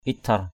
/it-tʱar/ (t.) dã man, vô nhân đạo; thô lỗ. inhumane; rude. sa ray hadiip itthar s% rY hd`{P i{TER một cuộc sống dã man. a savage life. baniai itthar b{=n`...